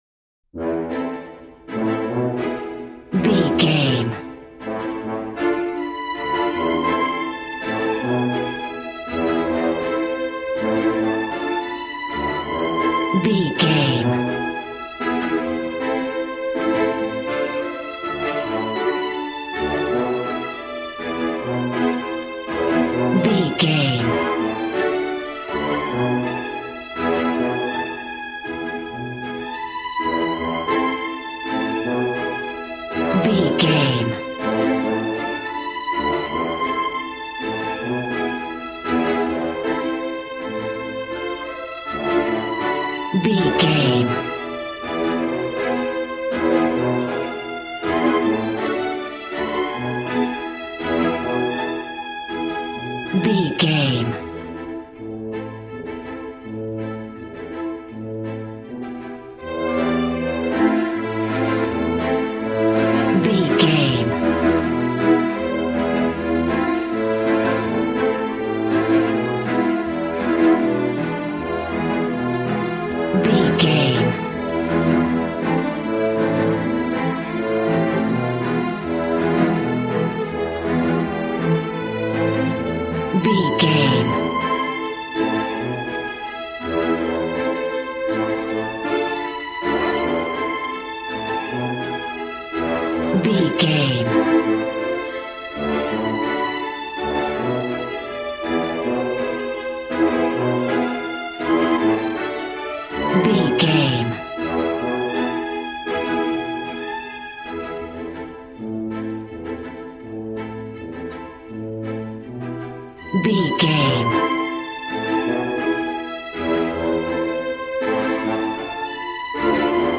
Valiant and Triumphant music for Knights and Vikings.
Regal and romantic, a classy piece of classical music.
Ionian/Major
brass
strings
violin
regal